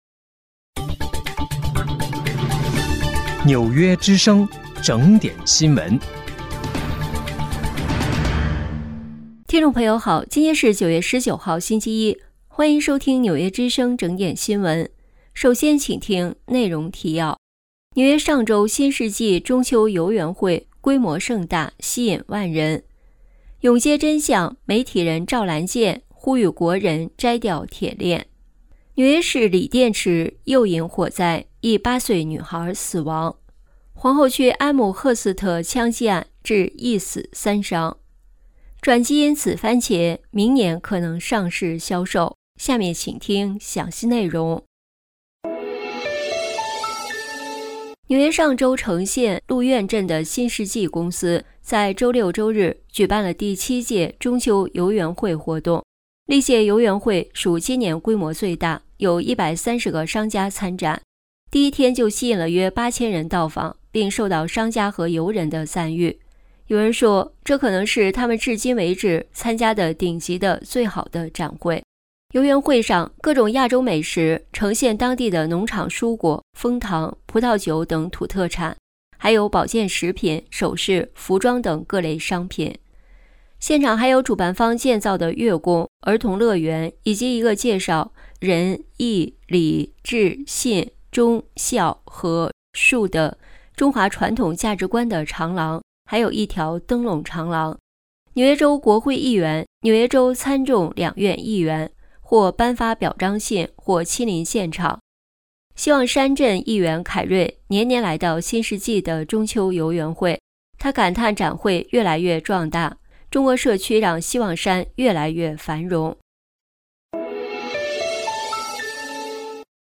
9月19号（星期一）纽约整点新闻。